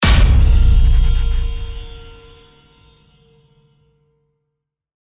Royalty free sounds: Hits/Impacts
mf_SE-9001-hit_and_chimes_2.mp3